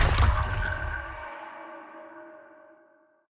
ogg / general / combat / aircraft / directional.ogg